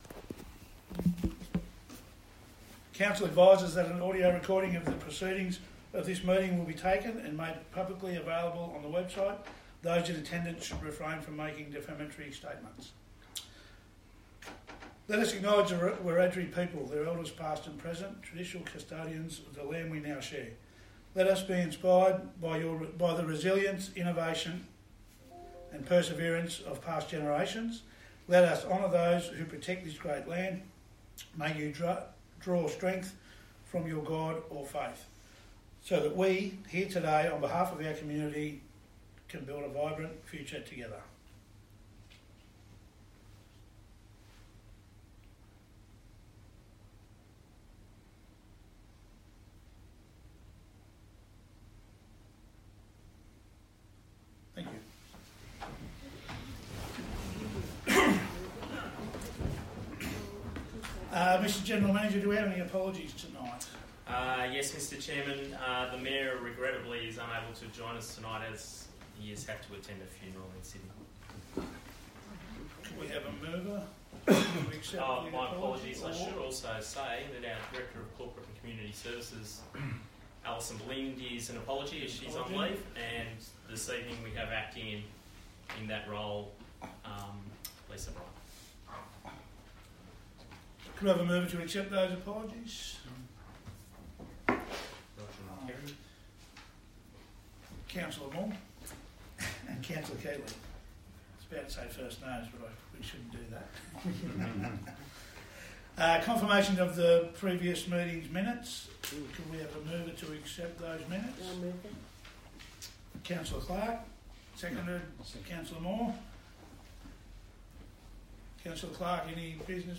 18 April 2023 Ordinary Meeting